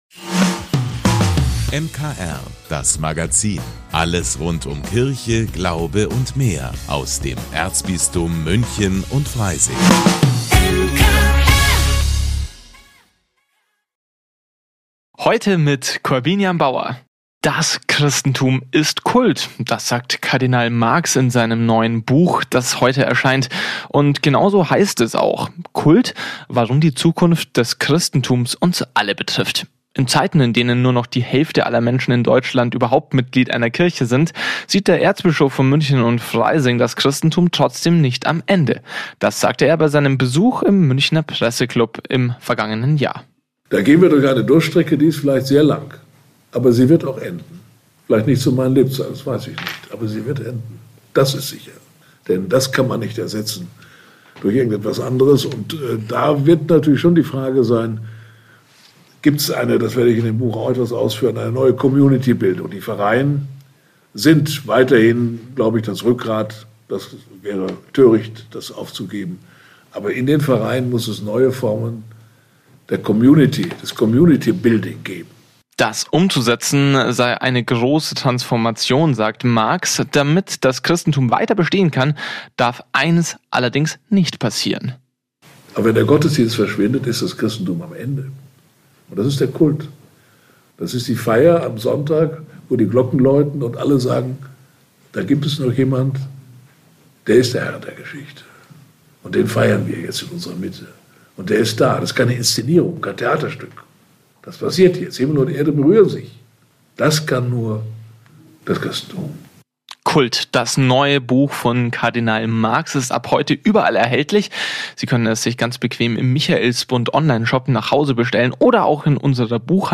In Zeiten, in denen nur noch die Hälfte aller Menschen in Deutschland Mitglied einer Kirche ist, sieht der Erzbischof von München und Freising das Christentum trotzdem nicht am Ende. Das sagte er bei seinem traditionellen Besuch im Münchner Presseclub im vergangenen Jahr.